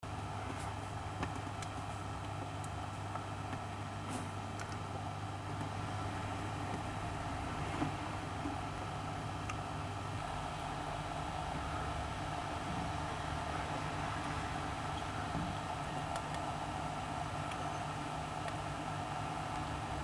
szmer filtra EHEIM 2211 - skompresowany do mp3 (Stereo) - 782 Kb